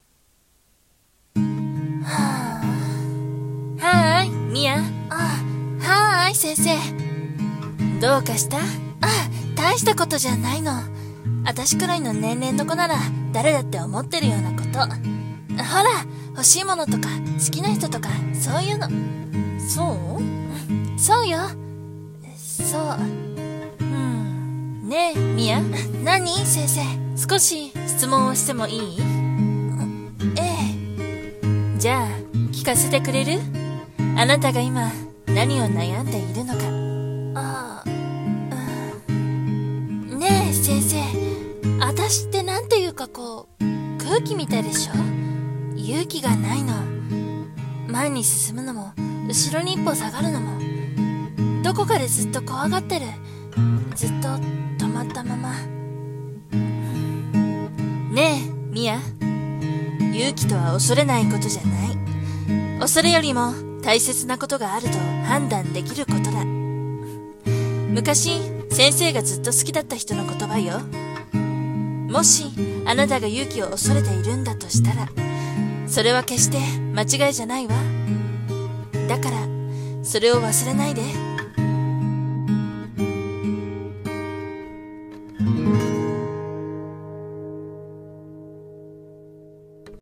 【海外ドラマ風声劇台本】「フレディ」【掛け合い】